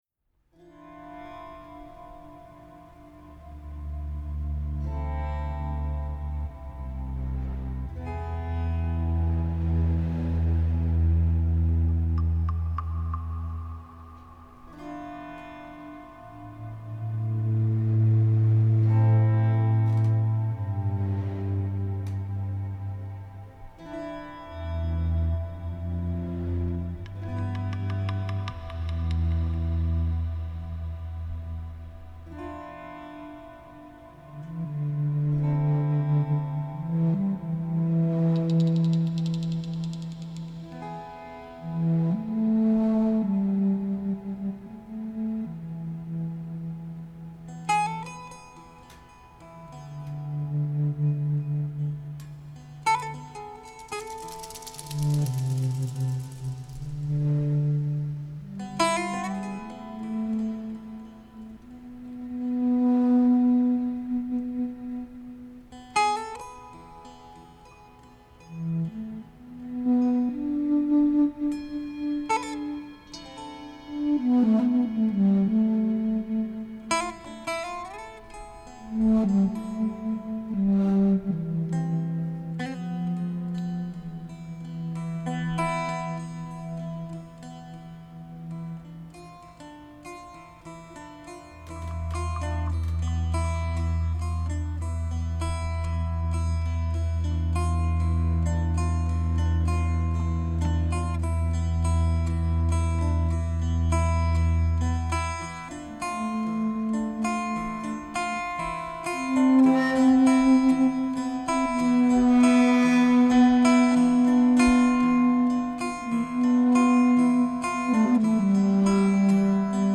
Live at UNH · CD Release Show 10/26/03